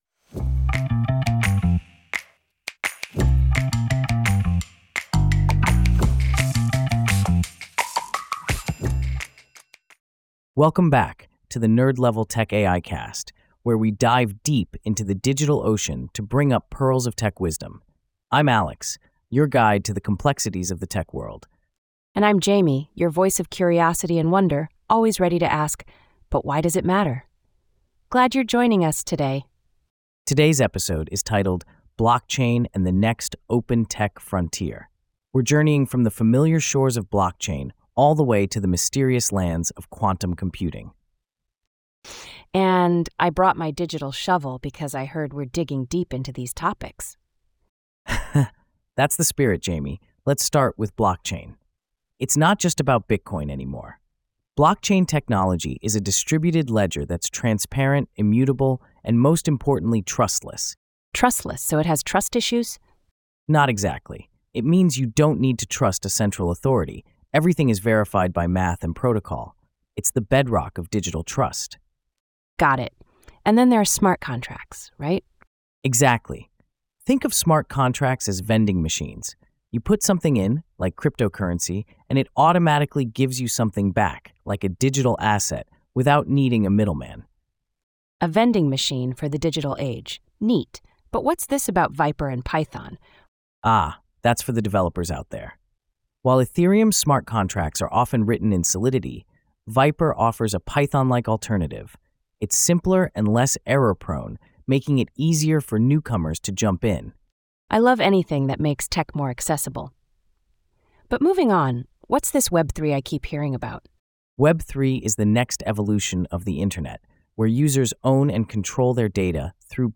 Listen to the AI-generated discussion